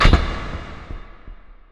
c_spiker_hit2.wav